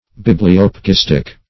Search Result for " bibliopegistic" : The Collaborative International Dictionary of English v.0.48: Bibliopegistic \Bib`li*op`e*gis"tic\ (b[i^]b`l[i^]*[o^]p`[-e]*j[i^]s"t[i^]k), a. Pertaining to the art of binding books.